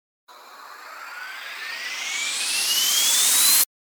FX-1392-RISER
FX-1392-RISER.mp3